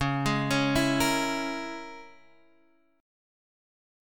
C# Minor 6th